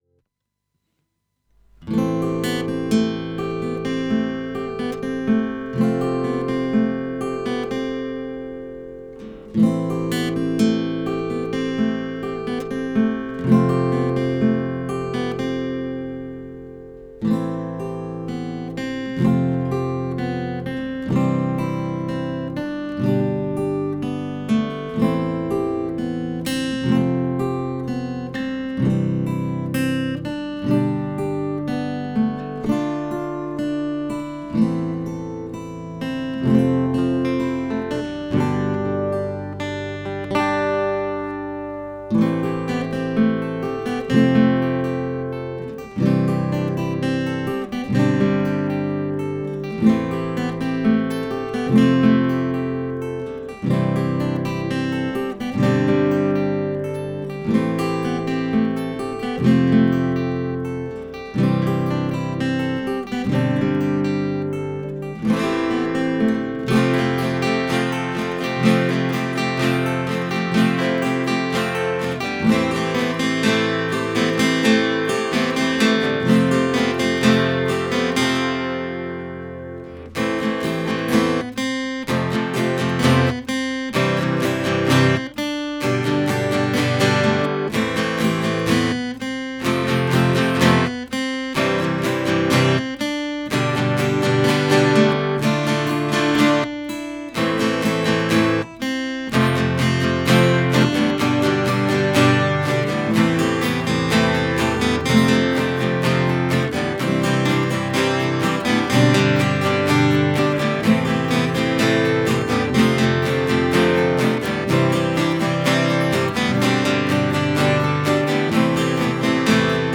It’s not a vocal cover, God no… I am not there yet.